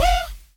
Vox 2 (UHHH).wav